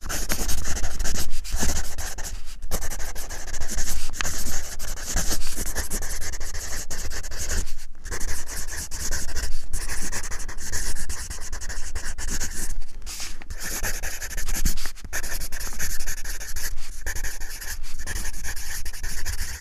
fo_pencil_writing_01_hpx
Pencil writing and erasing on a tablet of paper. Eraser, Pencil Writing, Pencil